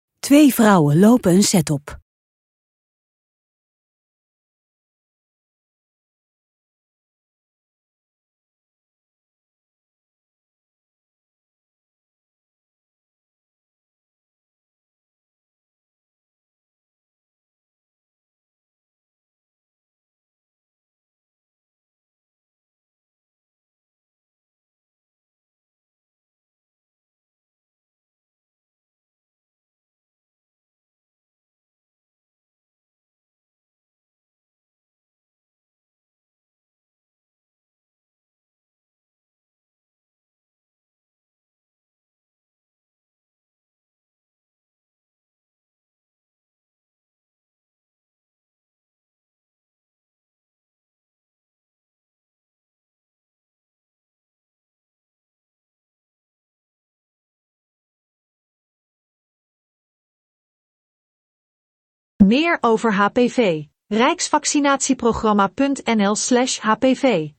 Rustige muziek
Twee vrouwen lopen een set op.